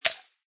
plasticice2.mp3